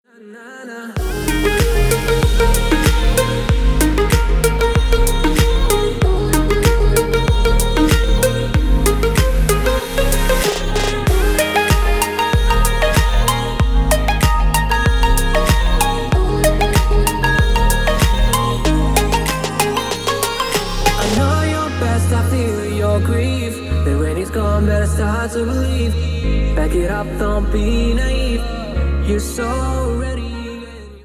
мужской вокал
tropical house
теплые
Стиль: tropical house